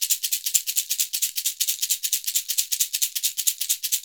Index of /90_sSampleCDs/Univers Sons - Basicussions/11-SHAKER133